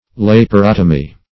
Laparotomy \Lap`a*rot"o*my\, n. [Gr.